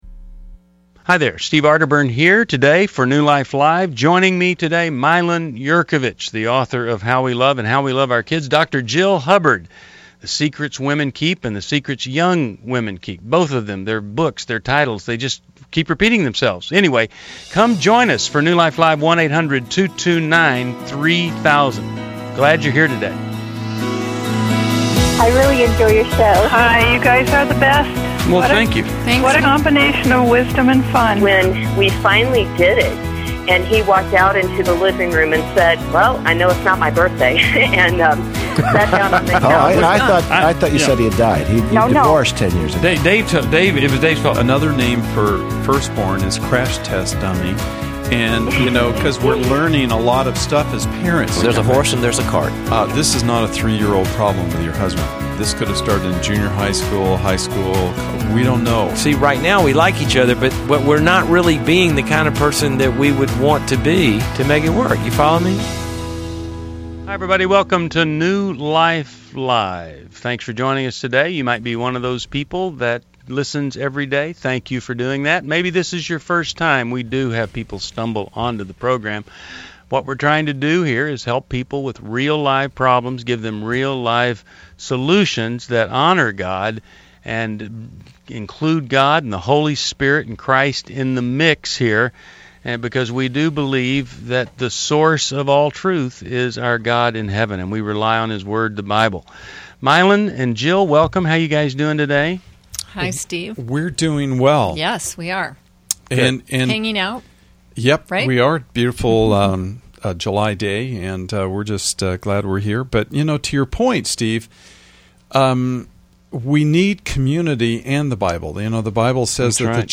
New Life Live: July 22, 2011 - Explore healing from infidelity, navigating Christian dating, and addressing abusive relationships with expert advice and caller insights.